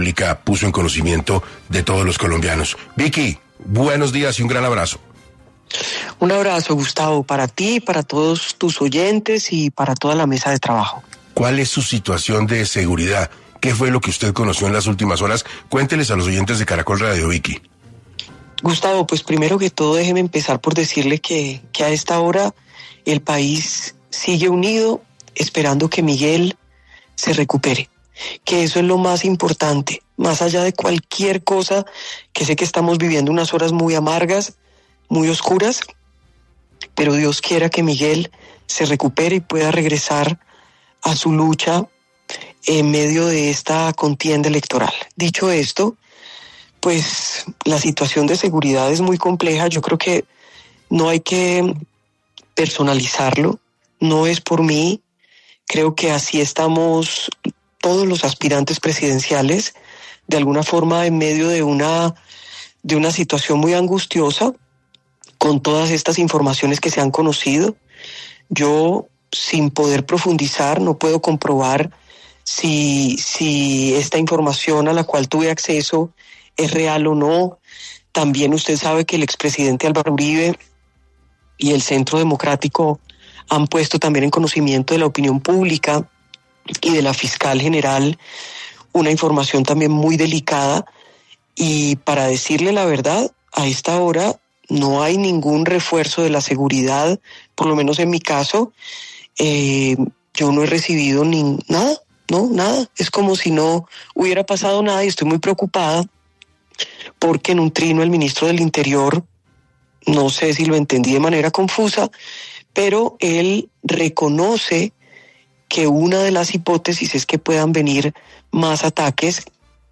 En entrevista con 6AM de Caracol Radio compartió detalles sobre una fuente de inteligencia militar que le advirtió sobre una posible escalada terrorista.